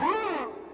Sound (2) -distorted.wav